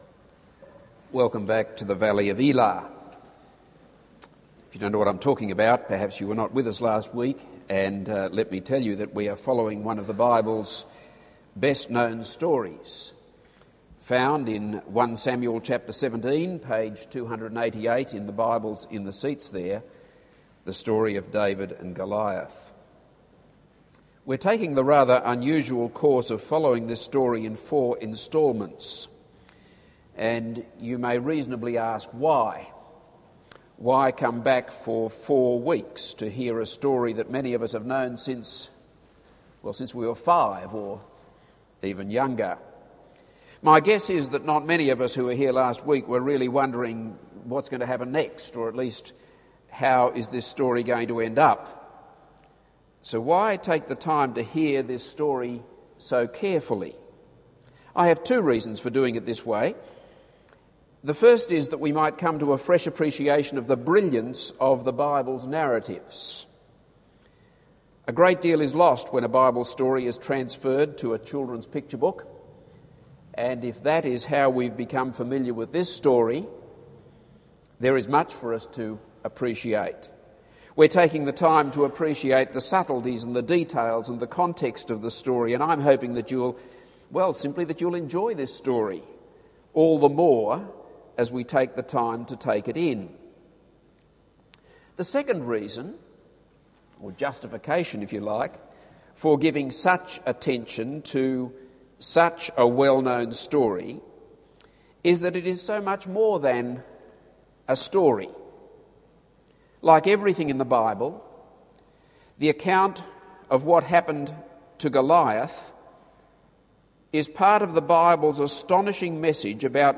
This is a sermon on 1 Samuel 17:12-30.